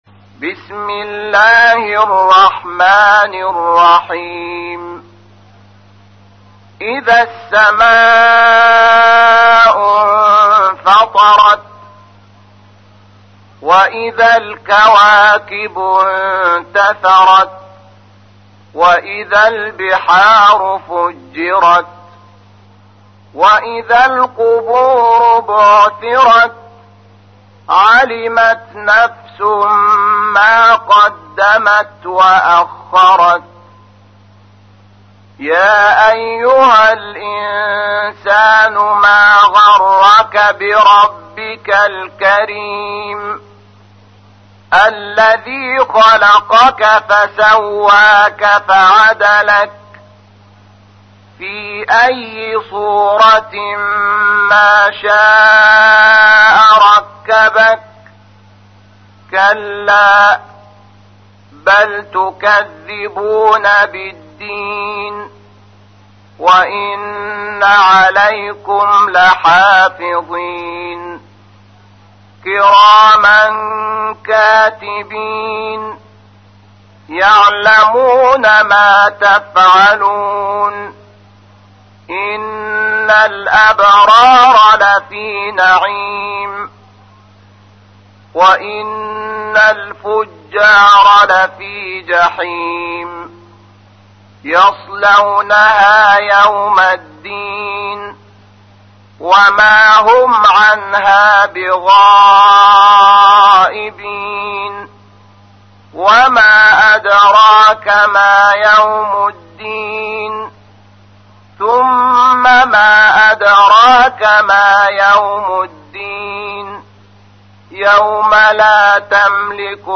تحميل : 82. سورة الانفطار / القارئ شحات محمد انور / القرآن الكريم / موقع يا حسين